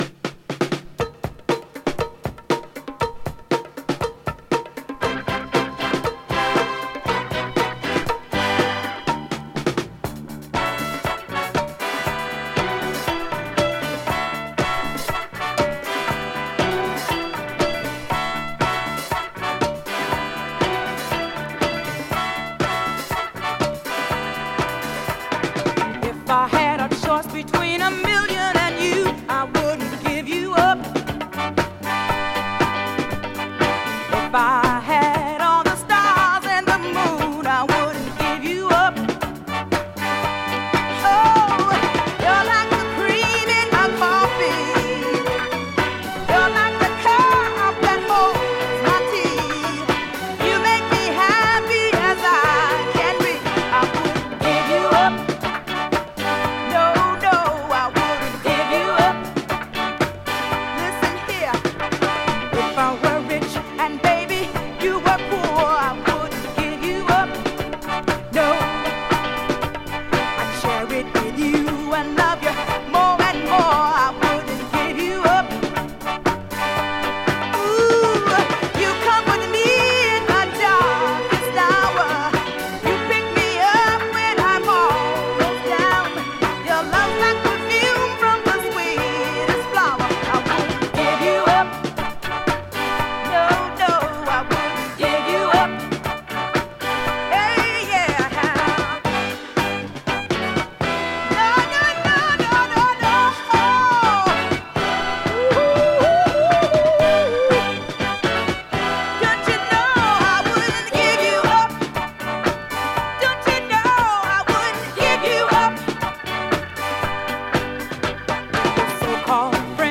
Click the link below to hear the interview conducted on 16th July 2018